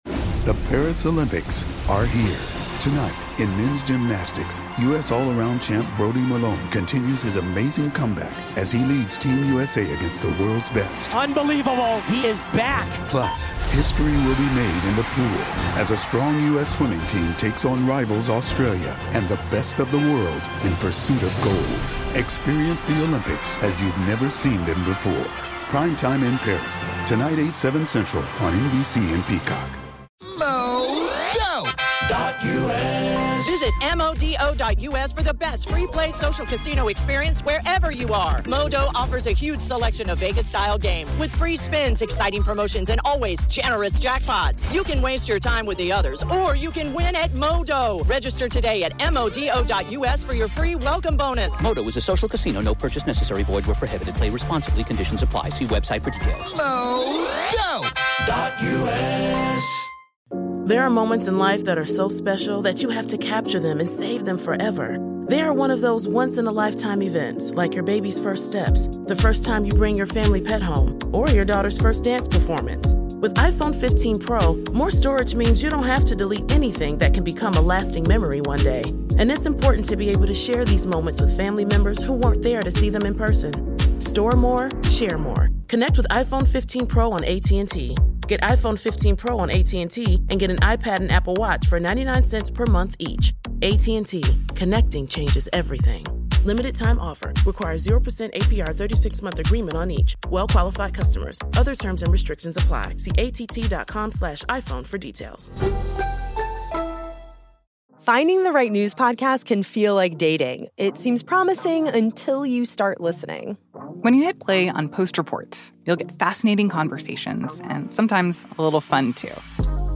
The John Adams story you are about to hear was given by David McCullough at The John Adams Institute in the Netherlands.
Here's David McCullough with the story of John Adams.